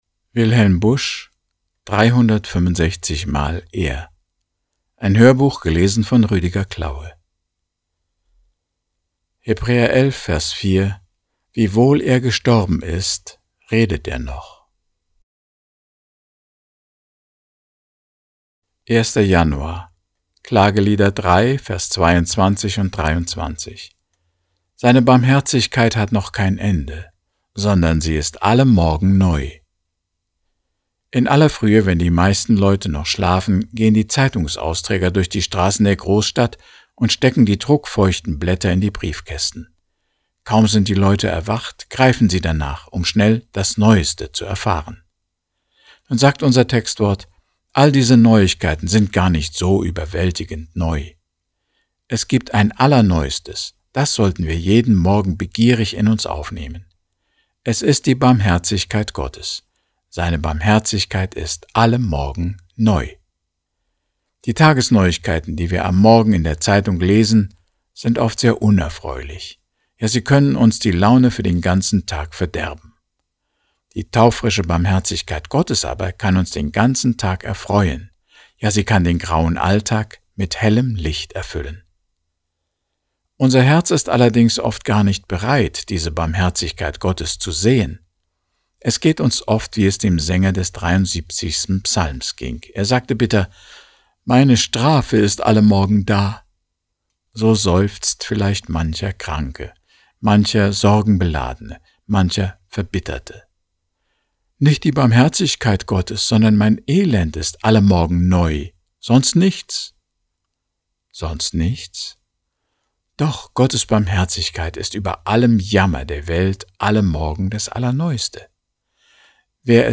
Tägliche Andachten
Inhalt Hörbuch